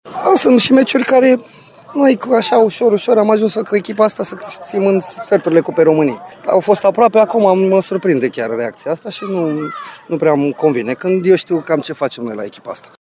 La finalul meciului, cei 40 de suporteri reșițeni prezenți la meci au scandat „Rușine să vă fie”, chiar în timpul declarațiilor de presă pe care le oferea antrenorul Flaviu Stoican.
Tehnicianul s-a arătat deranjat de acest mesaj, iar după sesiunea de declarații s-a dus la suporteri pentru a avea un dialog: